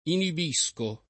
inibire v.; inibisco [ inib &S ko ], ‑sci